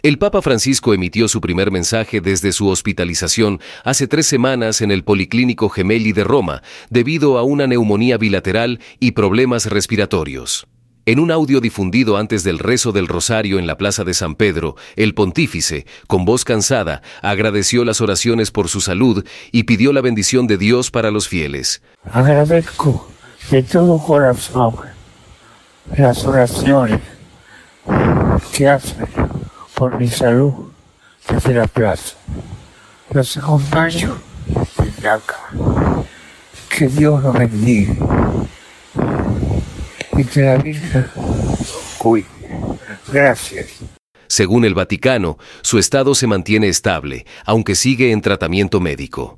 En un audio difundido antes del rezo del Rosario en la Plaza de San Pedro, el Pontífice, con voz cansada, agradeció las oraciones por su salud y pidió la bendición de Dios para los fieles.